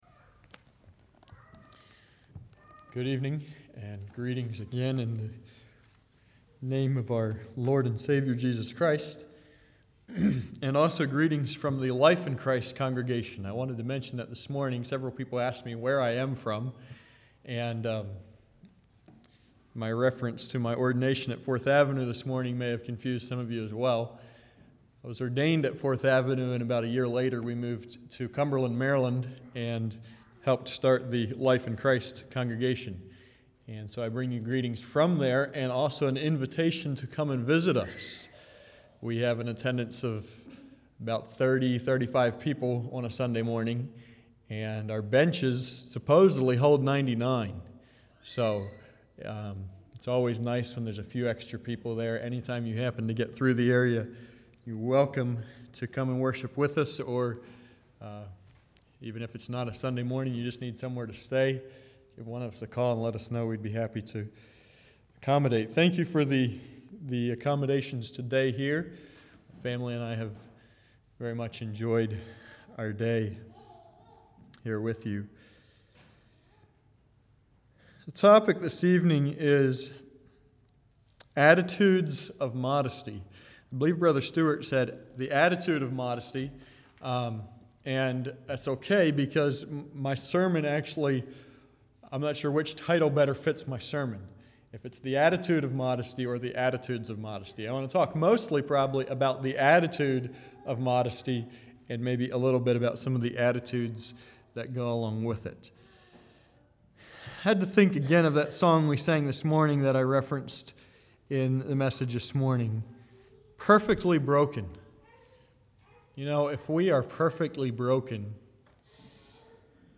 Play Now Download to Device Attitudes of Modesty 1 Congregation: Mount Joy Speaker
Sermon